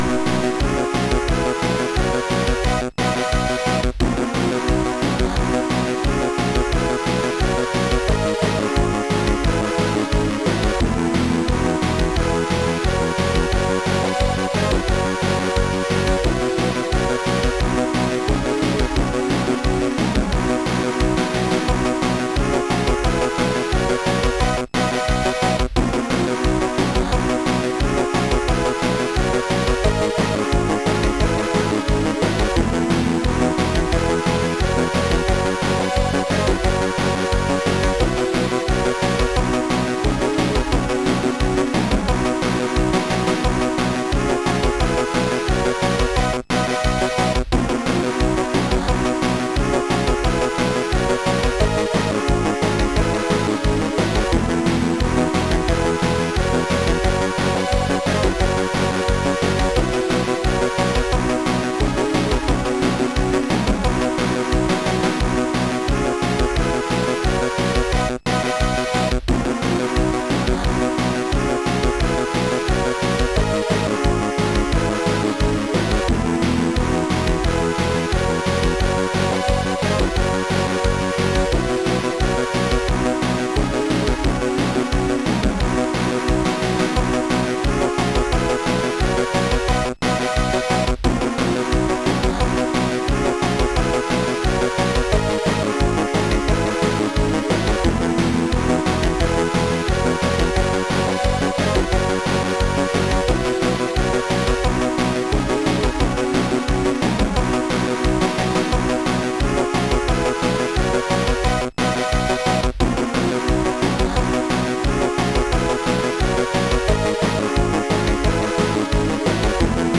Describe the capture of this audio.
Game music recorded by: SNDH Recording project Features digitized title soundtrack at 8.1KHz